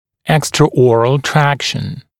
[ˌekstrə’ɔːrəl ‘trækʃn] [ˌэкстрэ’о:рэл ‘трэкшн] внеротовая тяга, внеротовая тракция